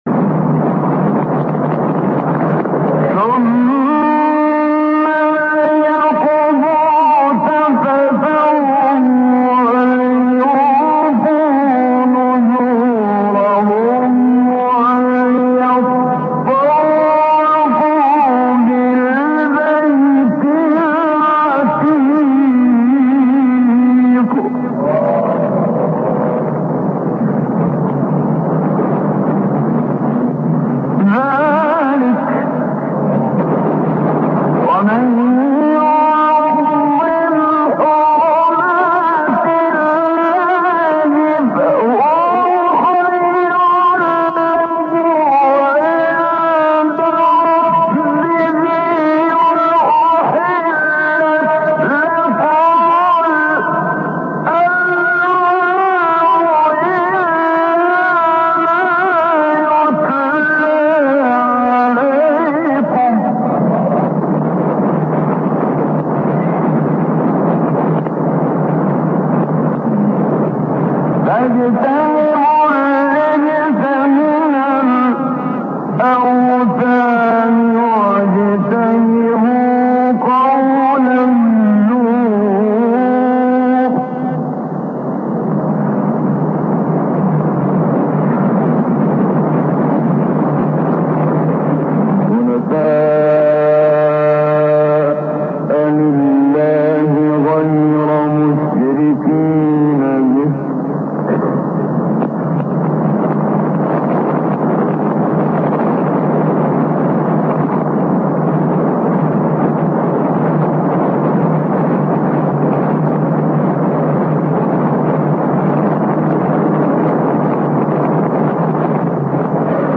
به مناسبت آغاز اعزام زائران ایرانی به حج ۱۴۰۱، آیات مربوط به حج با صدای قاریان برجسته مصری در کرسی تلاوت ارائه می‌شود.
تلاوت محمد صدیق منشاوی از آیات 29 تا 35 سوره مبارکه حج